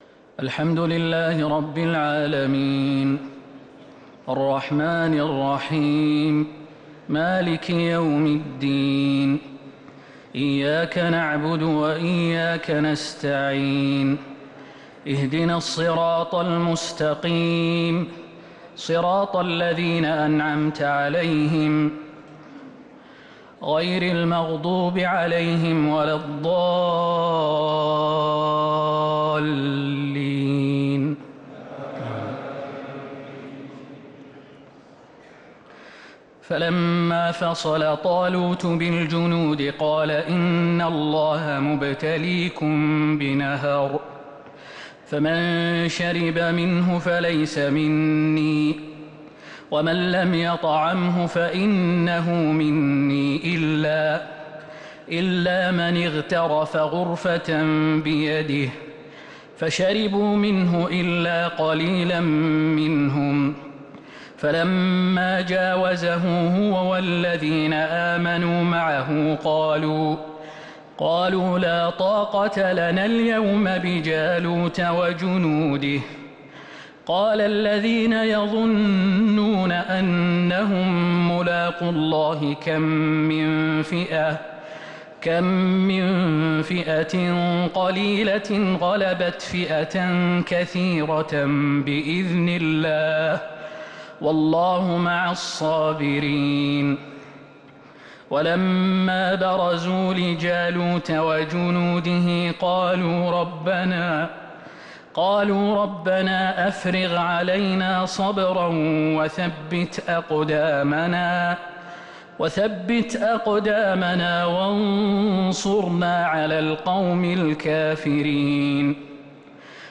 تراويح ليلة 3 رمضان 1447هـ من سورة البقرة {249-271} Taraweeh 3rd night Ramadan 1447H > تراويح الحرم النبوي عام 1447 🕌 > التراويح - تلاوات الحرمين